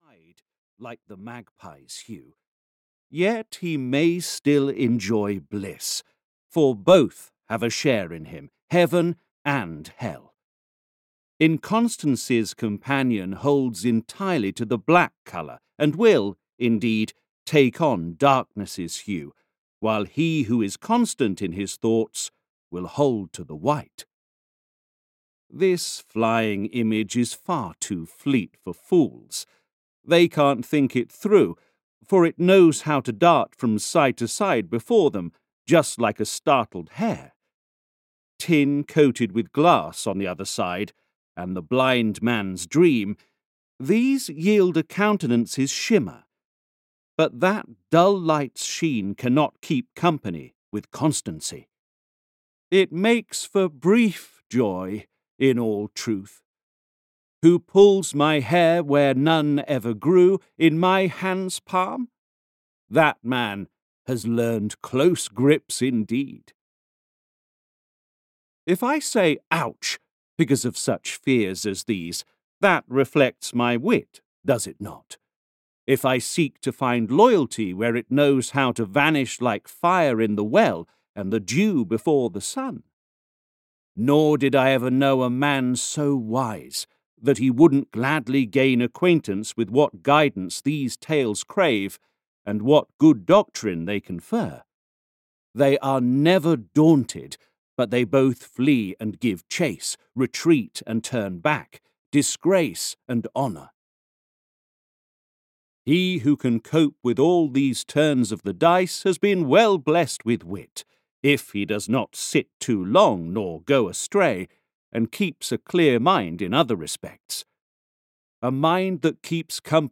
Parzival (EN) audiokniha
Ukázka z knihy